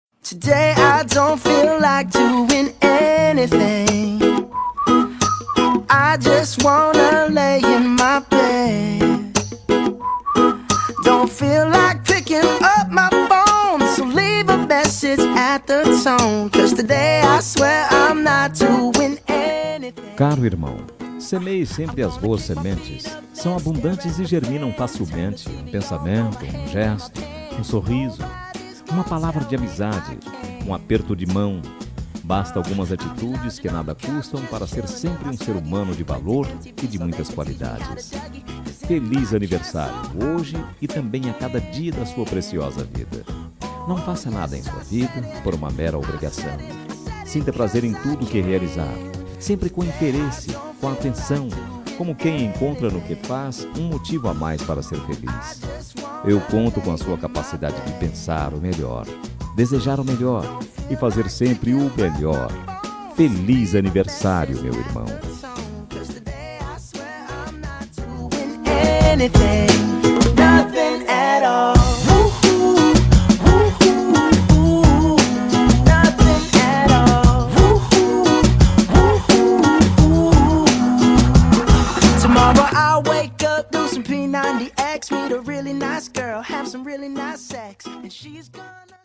Telemensagem de Aniversário de Irmão – Voz Masculina – Cód: 202235